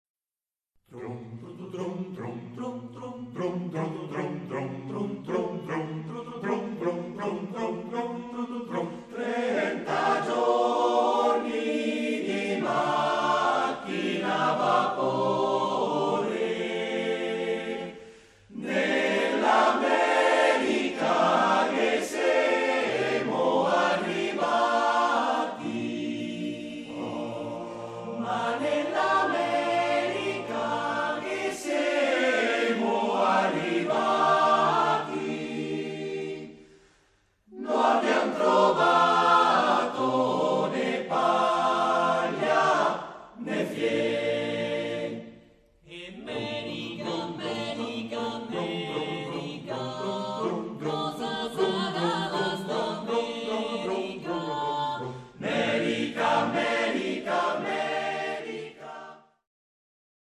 Written by Unknown
Performed by The Coro Marmolada
About the Coro Marmolada, aka the Marmolada Choir